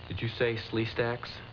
I apologize for the low sound quality of some of the dialog bites this time around.
did_you_say_Sleestaks.wav